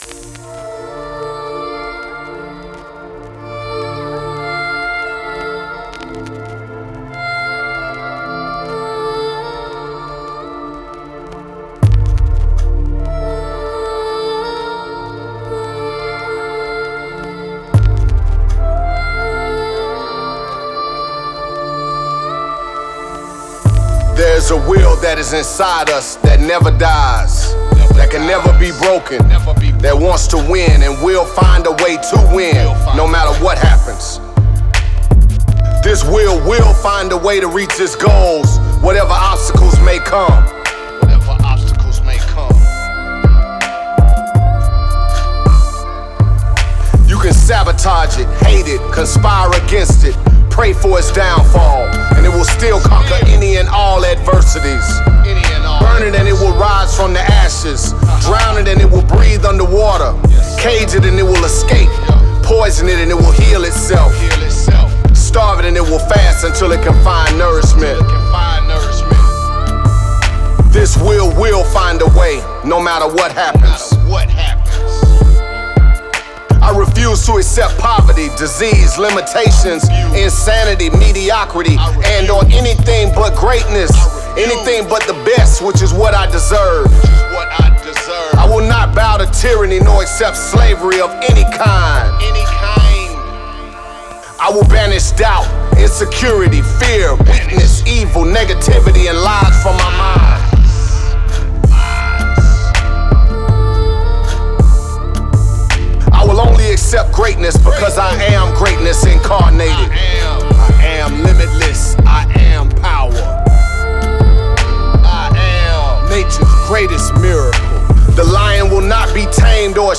spoken word and hip-hop beats